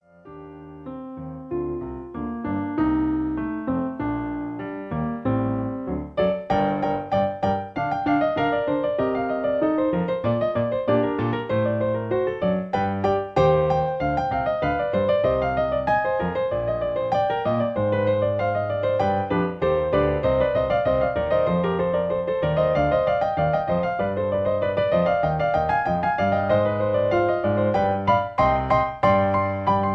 In G. Piano Accompaniment